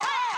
SouthSide Chant (33).wav